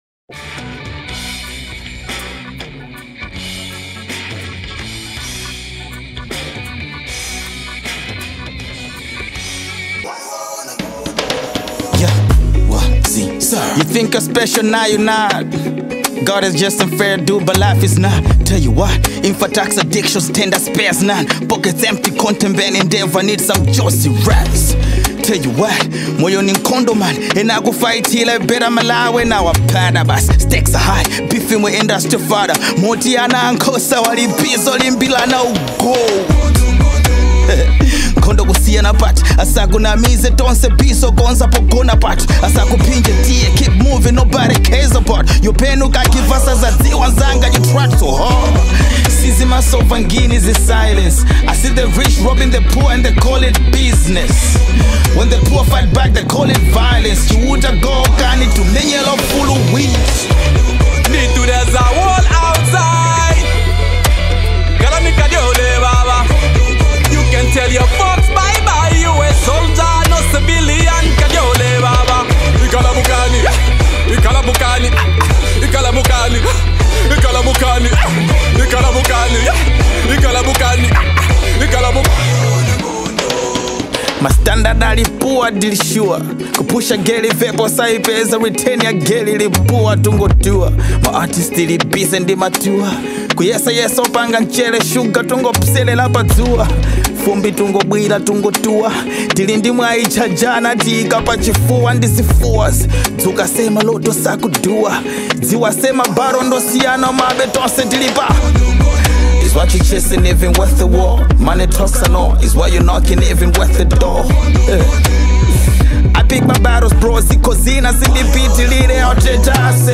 With sharp verses and heartfelt emotion